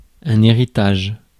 Ääntäminen
Synonyymit legs patrimoine succession Ääntäminen France: IPA: /e.ʁi.taʒ/ Haettu sana löytyi näillä lähdekielillä: ranska Käännös Substantiivit 1. pärus Muut/tuntemattomat 2. pärand Suku: m .